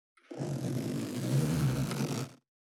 411,ジッパー,チャックの音,洋服関係音,ジー,バリバリ,カチャ,ガチャ,シュッ,パチン,ギィ,カリ,カシャ,スー,
ジッパー効果音洋服関係